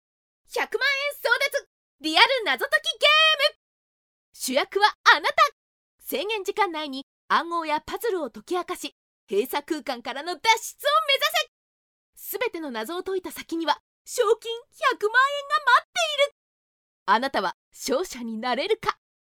– Narration –
Bright
Highly excited
female68_3.mp3